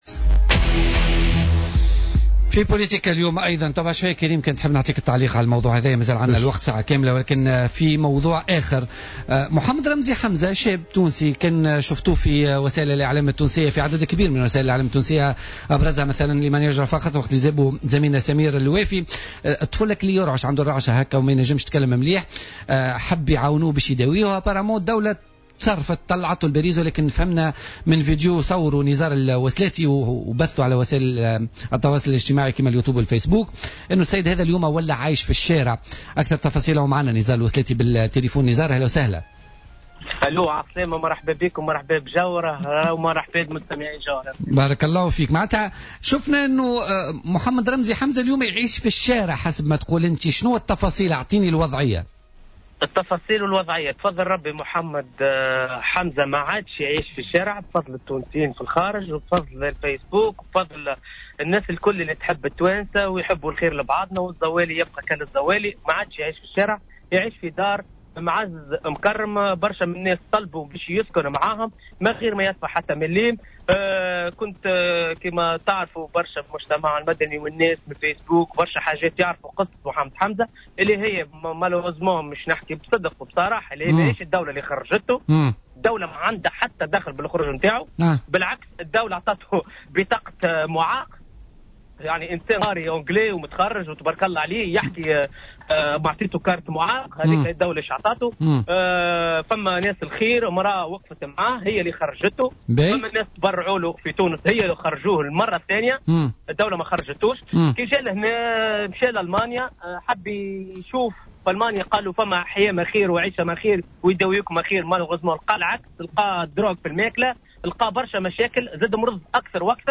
وأضاف في اتصال هاتفي مع برنامج "بوليتيكا" أنه تم ترتيب اجراءات الاقامة له في فرنسا حتى يتمكن من العلاج، متابعا أنه تم تجميع المال اللازم لاقامته وعلاجه بفرنسا في ظروف محترمة.